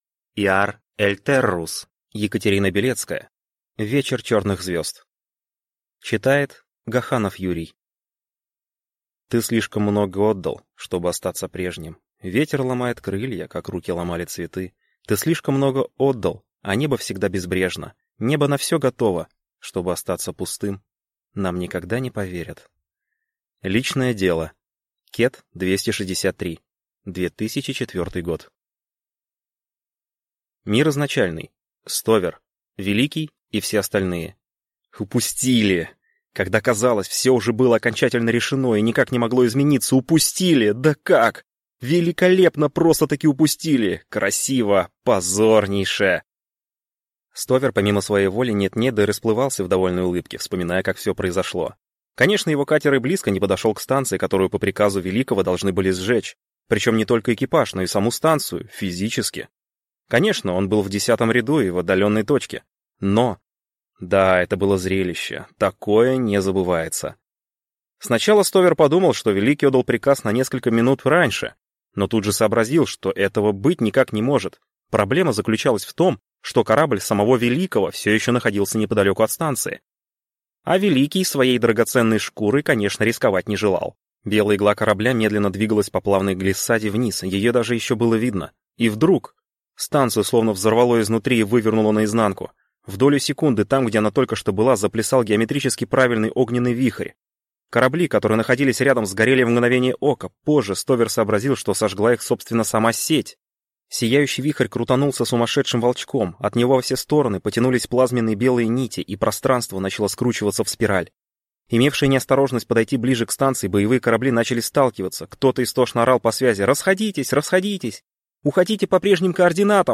Аудиокнига Вечер черных звезд | Библиотека аудиокниг